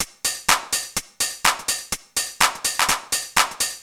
Index of /musicradar/retro-house-samples/Drum Loops
Beat 20 No Kick (125BPM).wav